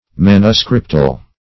Manuscriptal \Man"u*script`al\, a.